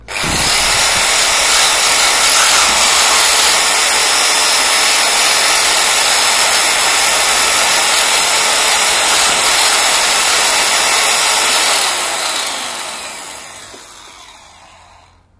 启动ibook
描述：启动g3 ibook，便宜的接触麦克风
Tag: 现场录音